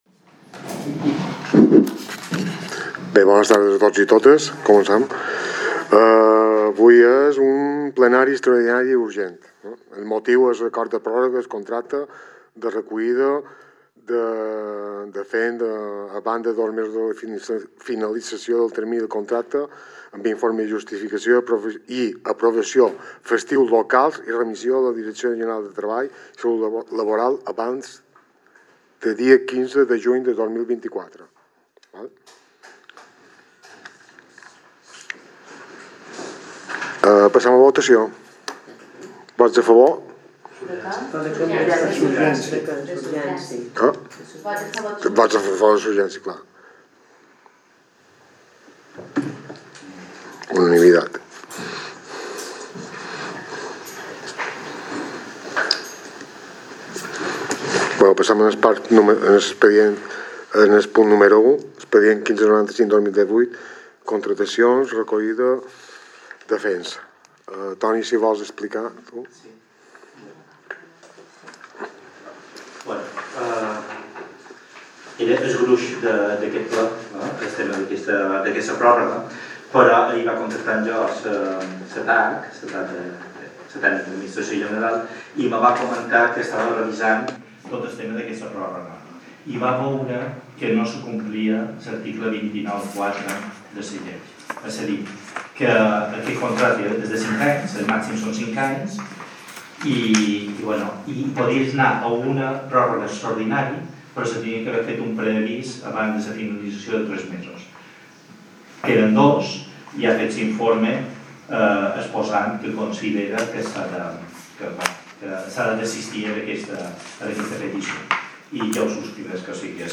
Sessió del Ple Extraordinari i Urgent de l'Ajuntament de Bunyola que es celebrarà el proper dijous dia 29 de maig a les 19:00 hores, a la Sala Plenaris Ajuntament.